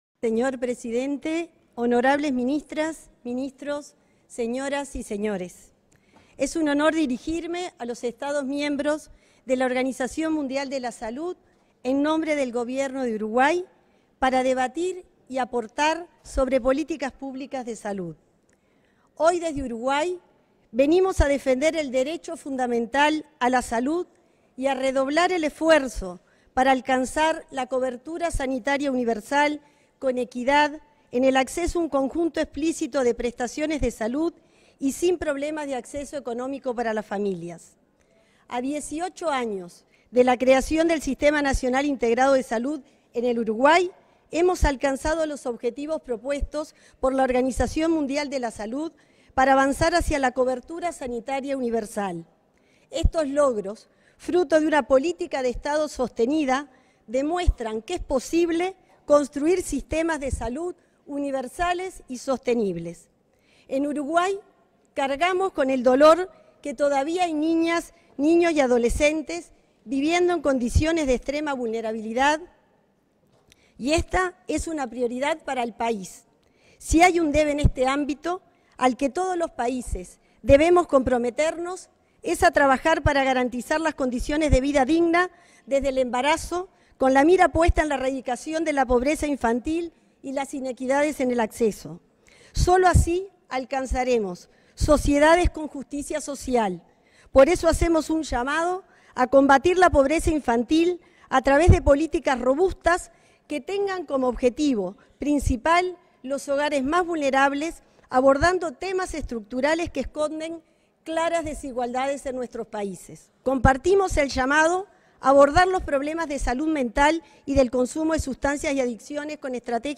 Palabras de la ministra de Salud Pública, Cristina Lustemberg
Palabras de la ministra de Salud Pública, Cristina Lustemberg 22/05/2025 Compartir Facebook X Copiar enlace WhatsApp LinkedIn La ministra de Salud Pública, Cristina Lustemberg, participó en la 78.ª Asamblea Mundial de la Salud, en Ginebra, Suiza, en la que disertó en representación de Uruguay.